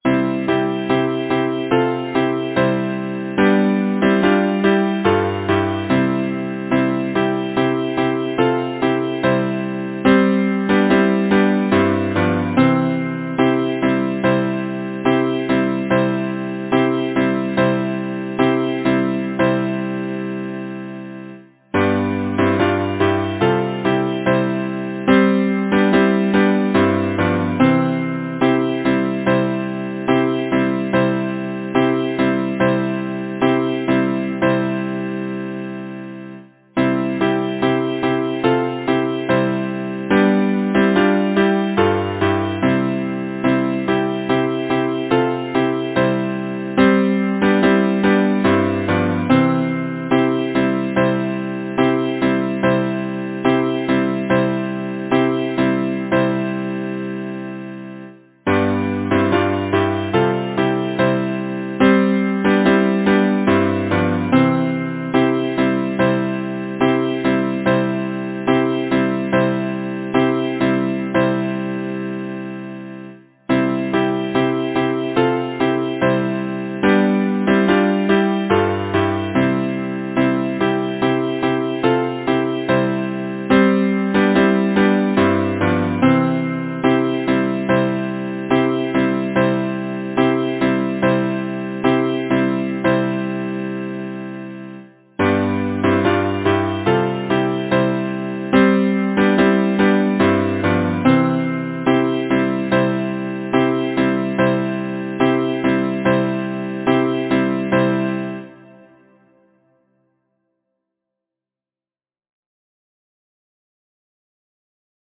Title: The Whippoorwill Composer: Cyrus Cornelius Pratt Lyricist: Nellie J. Maincreate page Number of voices: 4vv Voicing: SATB Genre: Secular, Partsong
Language: English Instruments: A cappella